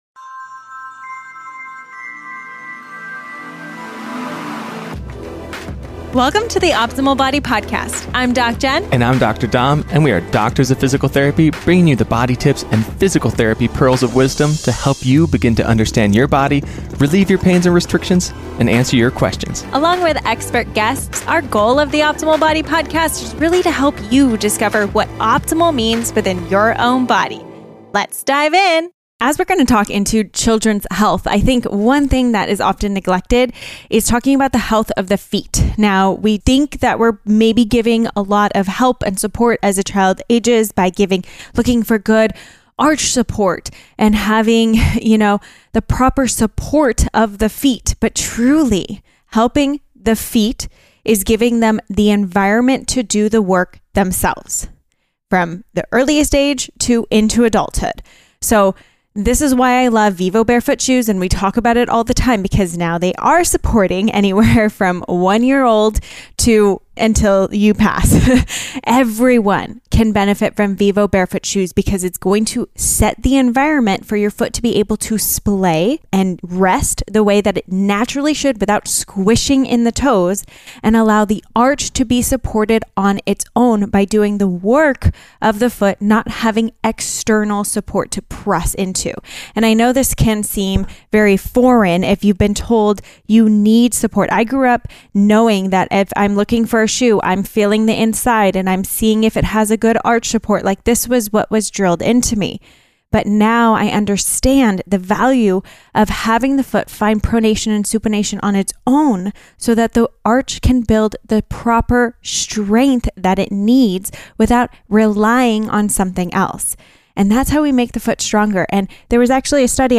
What You Will Learn in This Interview